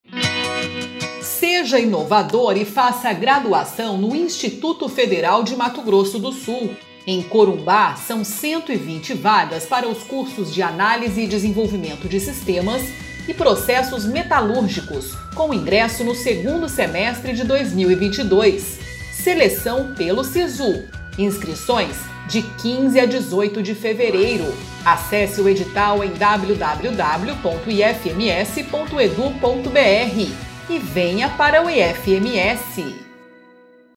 Áudio enviado às rádios para divulgação institucional do IFMS.